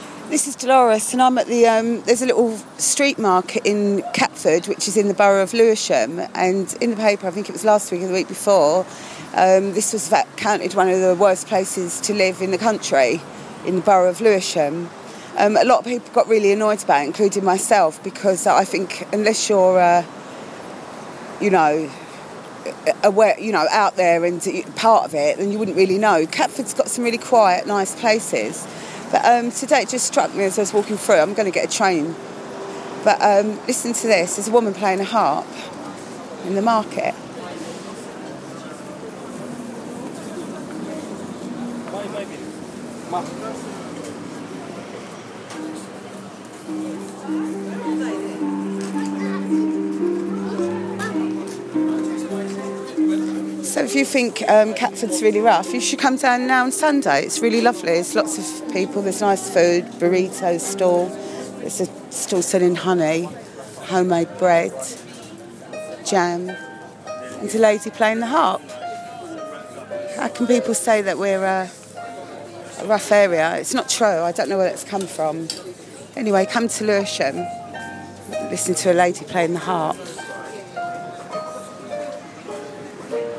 Not true, I'm listening to a harpist in the market